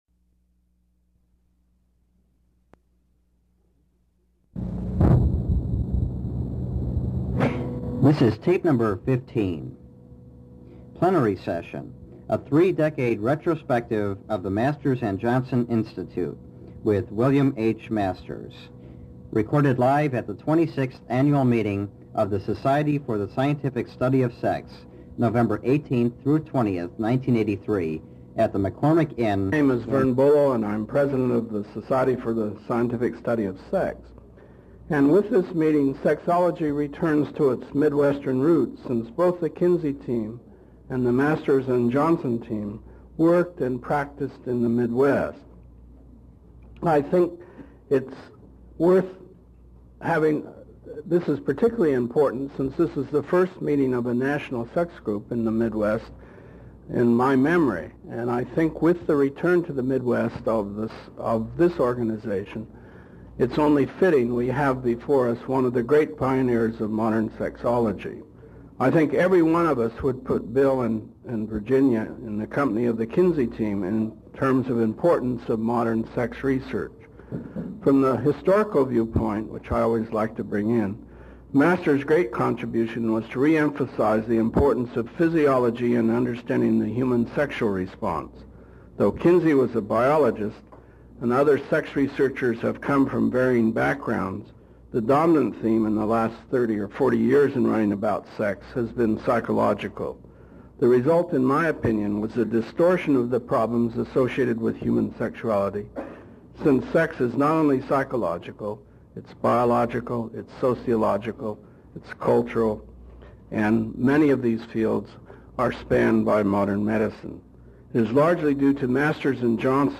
William H Masters 30 year Retrospective on Sex Research presented at the Society for the Scientific Study of Sex 26th Annual Meeting in Chicago in 1983.